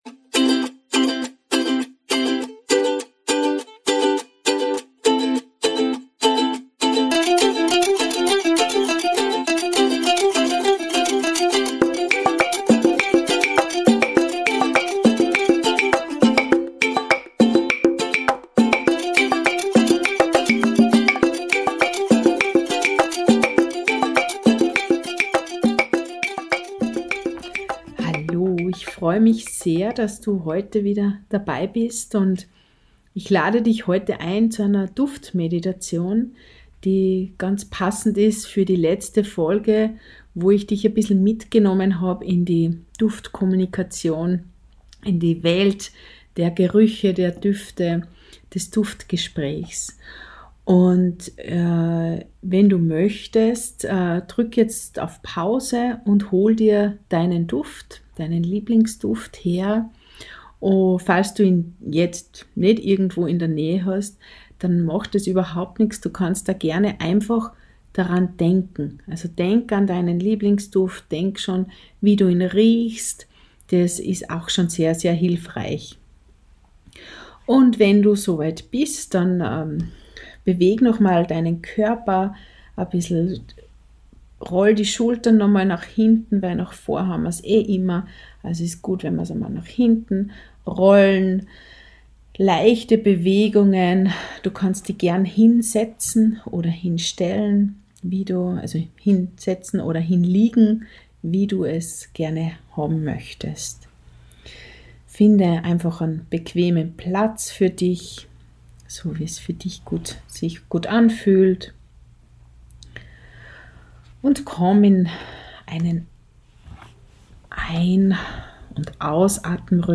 Wir erkunden eine Vielzahl von natürlichen Aromen, von beruhigender Lavendel bis hin zu erfrischender Minze, und lassen sie auf uns wirken. Während du dich entspannst, führe ich dich durch verschiedene Atemtechniken, die dir helfen, dich zu zentrieren und den Moment vollständig zu genießen.